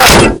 bhit_helmet-1_LgpLJPe.mp3